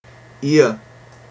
• /iə/ is phonetically [iə] (